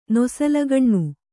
♪ nosalagaṇṇu